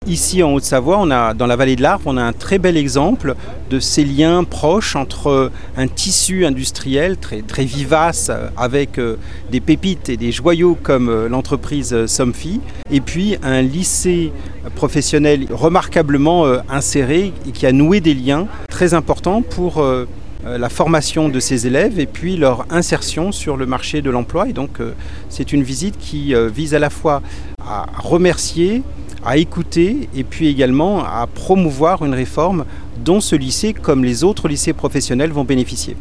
On écoute Pap Ndiaye, le ministre de l’éducation nationale.